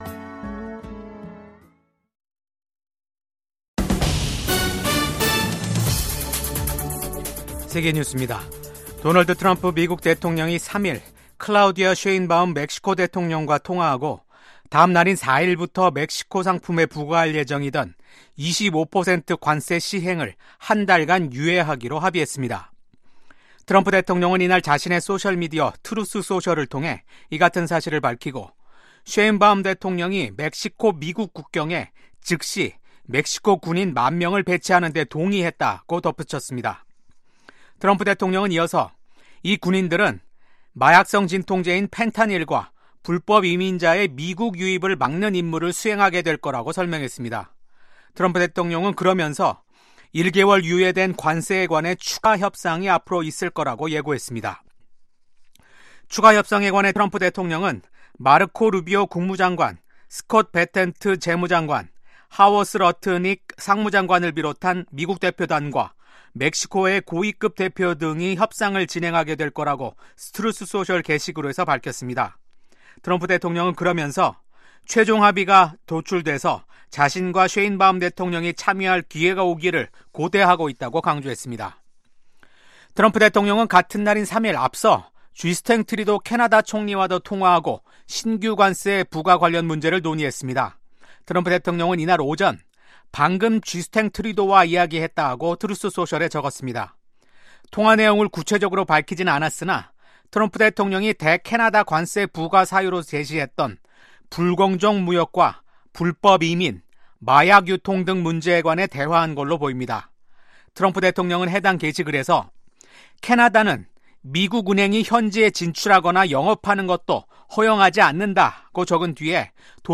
VOA 한국어 아침 뉴스 프로그램 '워싱턴 뉴스 광장'입니다. 마르코 루비오 국무장관이 미국이 세계 모든 문제를 짊어지는 현 상황을 비정상적이라고 평가하며 외교 초점을 ‘미국의 이익’에 맞출 것이라고 밝혔습니다. 루비오 국무장관이 불량국가라고 언급한 것에 대한 반응으로 북한은 도널드 트럼프 행정부 출범 이후 첫 대미 비난 담화를 냈습니다.